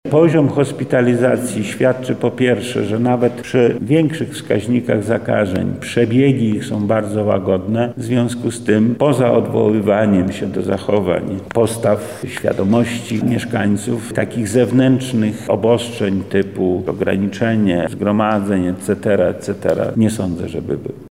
• mówi wojewoda Lech Sprawka.